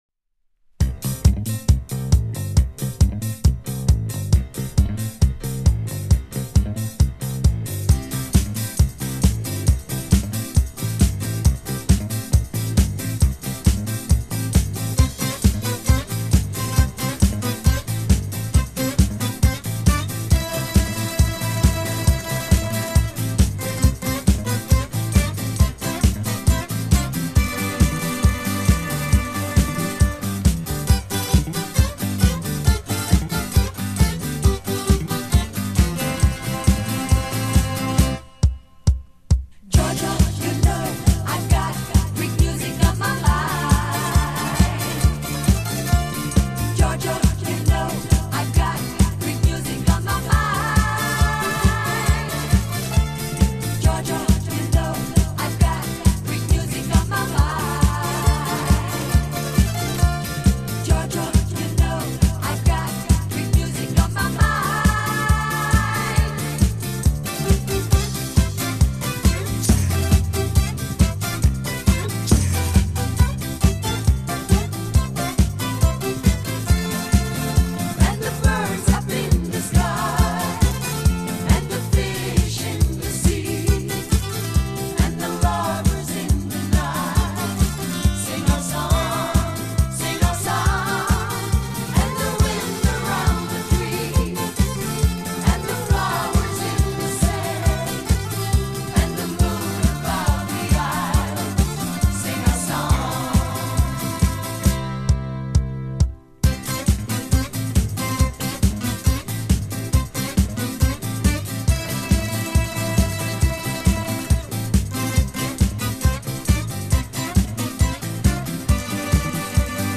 Ну, бузуки всегда не очень привычно и красивао звучит